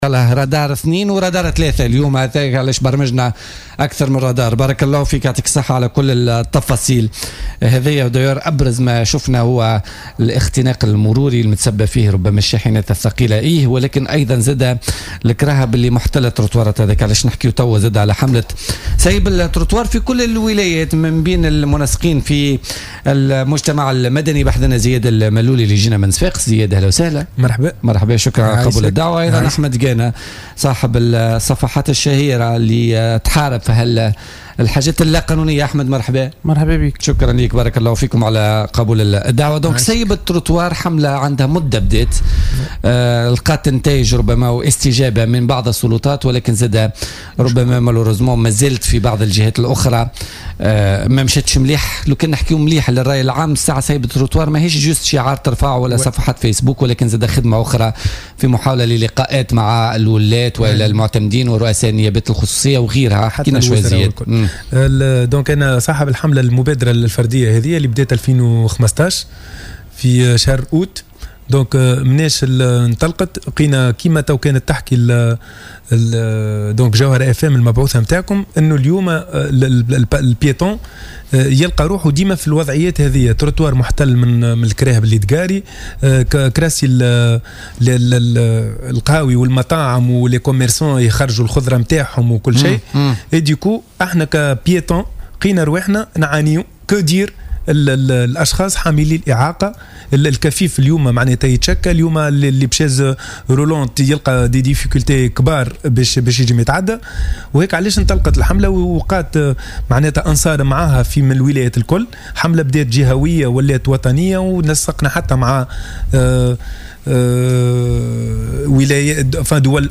وأكد ضيفا برنامج "بوليتيكا"، الجانب الردعي لمقاومة هذه الظاهرة ومراجعة النصوص القانونية.